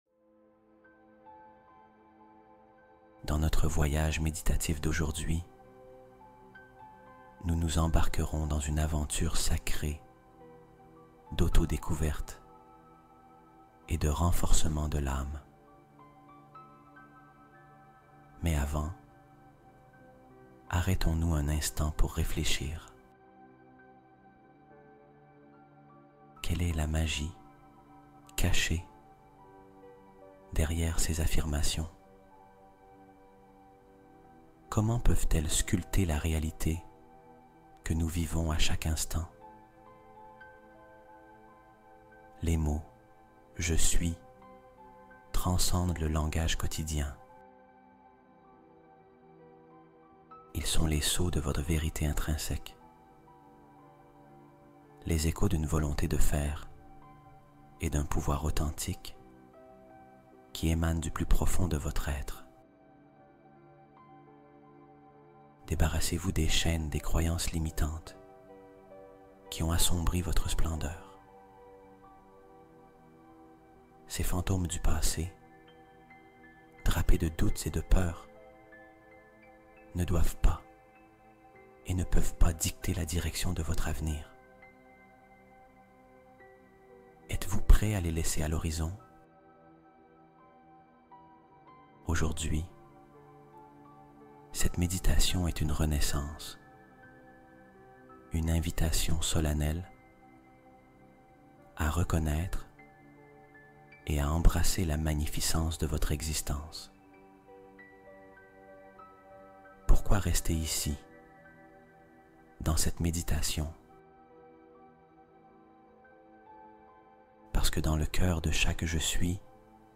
Hypnose du sommeil : accéder à des réponses par l’inconscient